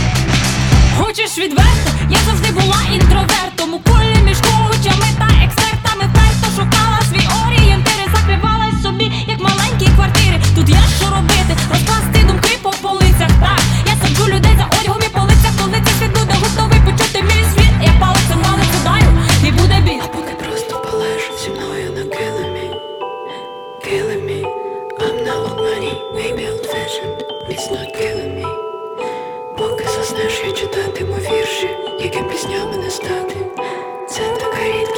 Pop
Жанр: Поп музыка / Украинские